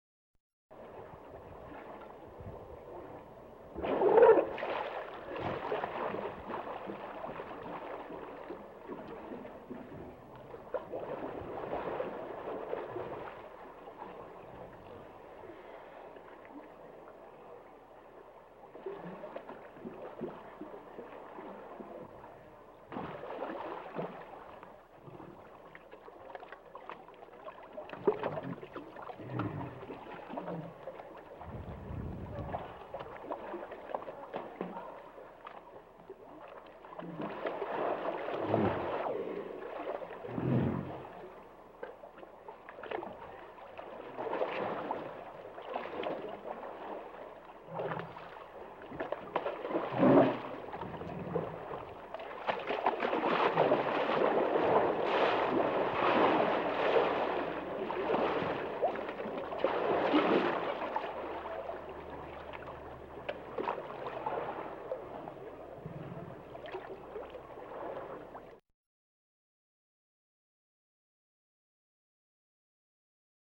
Hippo in Water Sound
animal
Hippo in Water